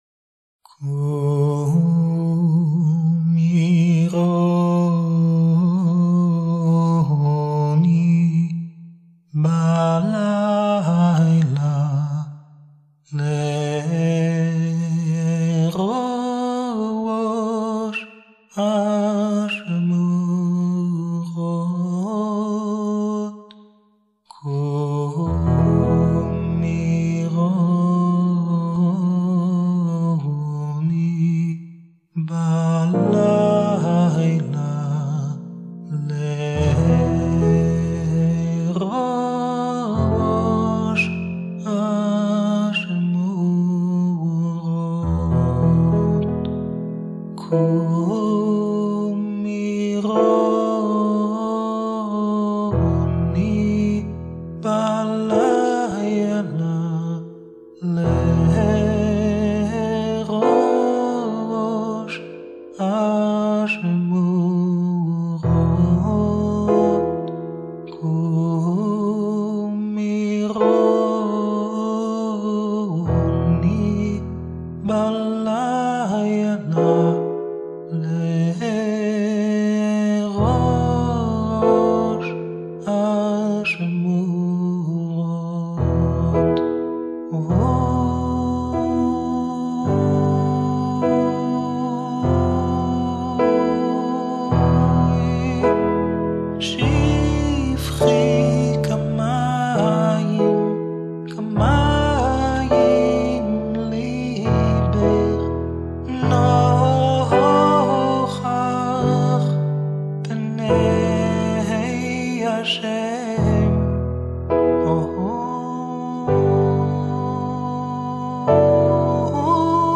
Extrait de Eikha 2, 19a inspiré d’un air Breslav